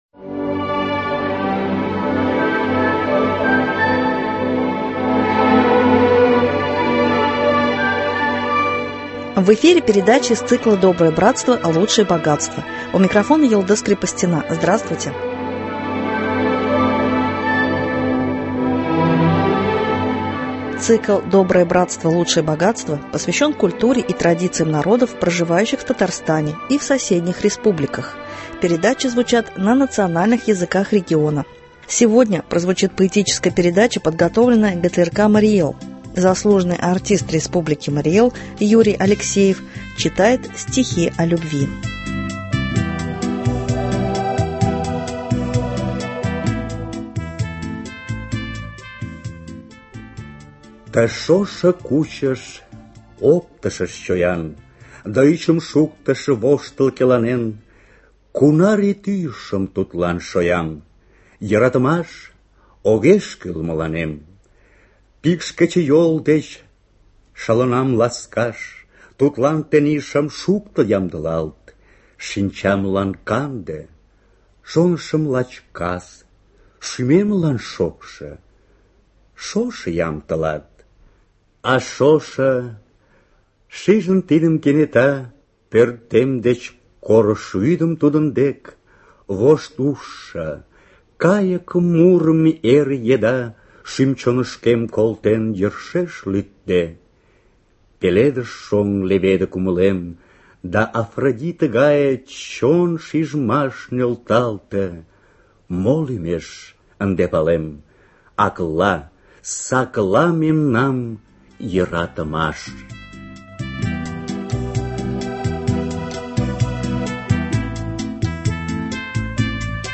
Цикл посвящен культуре и традициям народов, проживающих в Татарстане и в соседних республиках, передачи звучат на национальных языках региона. Сегодня прозвучит поэтическая передача